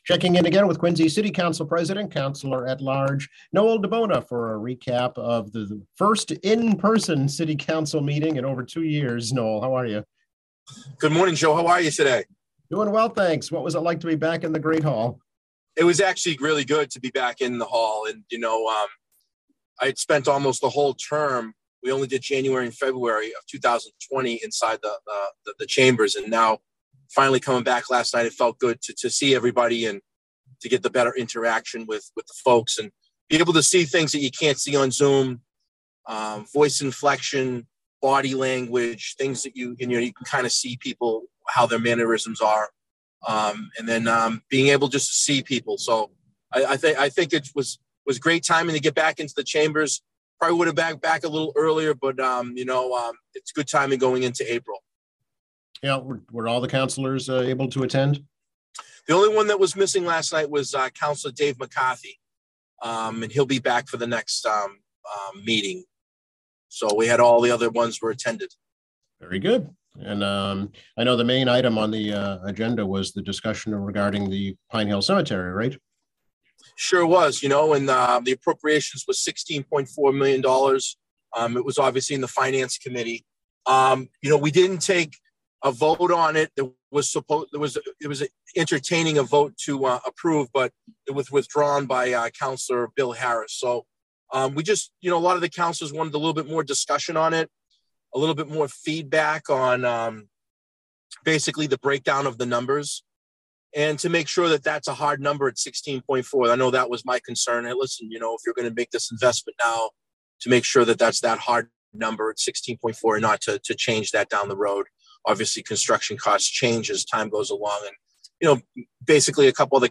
Quincy City Council President Noel DiBona explains the actions taken at the first in-person city council meeting in over 2 years on April 4, including a proposed expansion of Pine Hill Cemetery, and a plan by the VA to close the North Quincy health center.